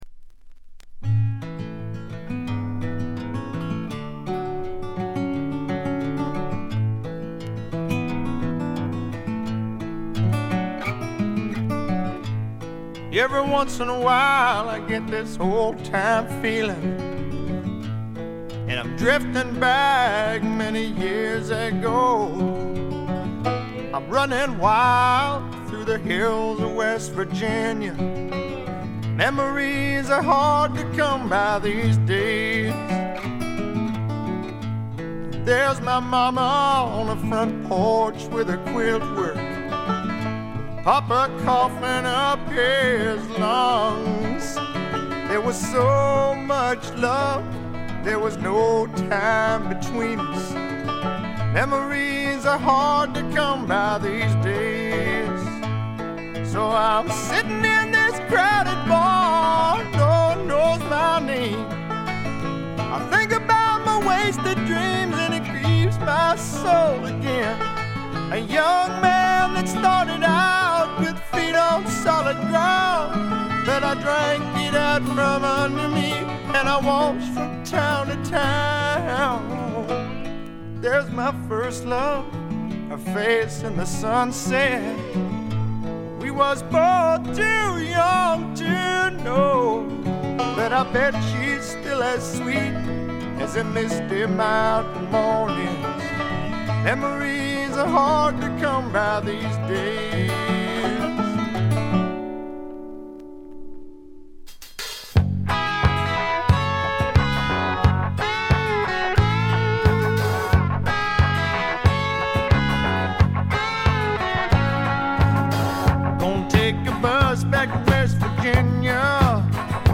部分試聴ですがほとんどノイズ感無し。
70年代末期にあって、あっぱれなスワンプ魂（ザ・バンド魂）を見せてくれました。
試聴曲は現品からの取り込み音源です。